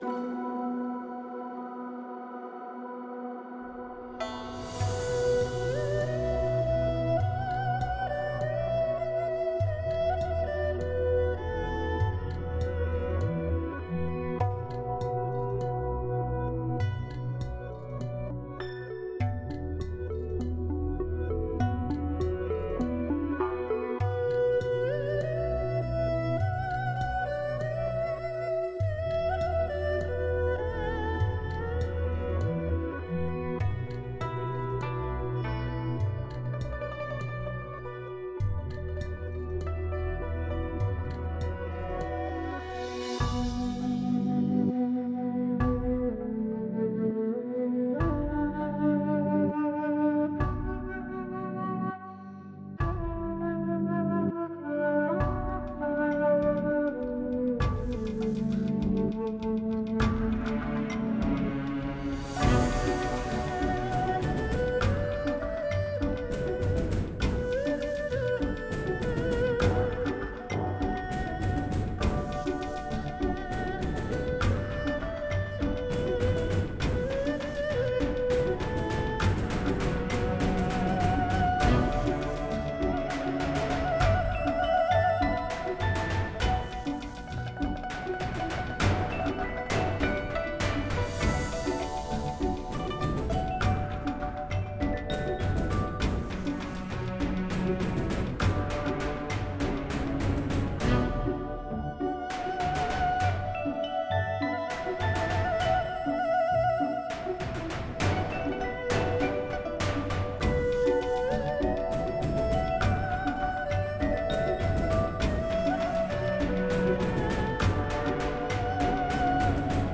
Versione strumentali (no voce)